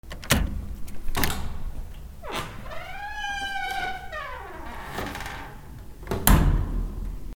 マンションエントランス
/ K｜フォーリー(開閉) / K05 ｜ドア(扉)
『ガチャバタンキィィ』